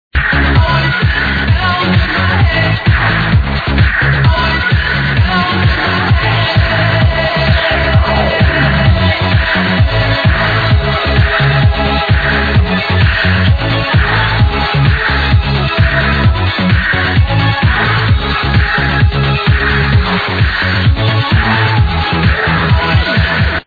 Swedish tech-house material, darn good.